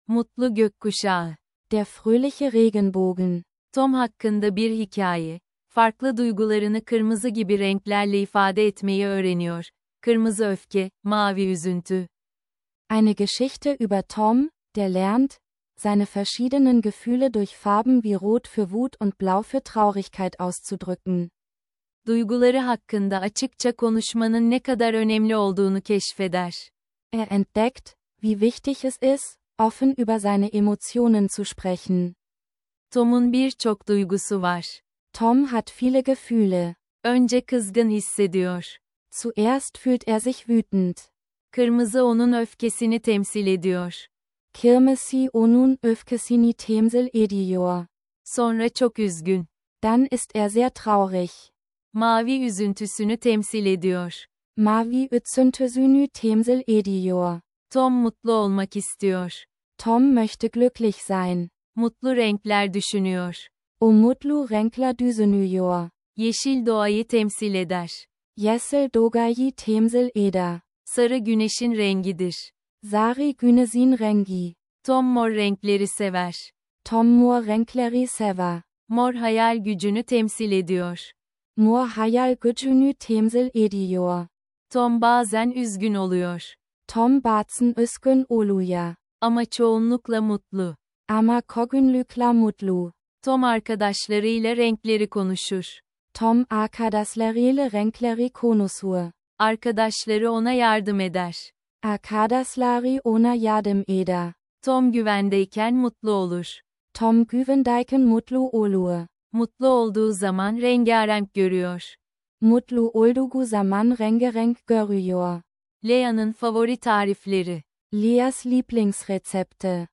Erlebe mit SynapseLingo, wie du mit spannenden Geschichten auf Türkisch in den Alltag eintauchen kannst! Diese Episode bietet eine Mischung aus emotionalem Geschichtenerzählen und praktischen Sprachübungen.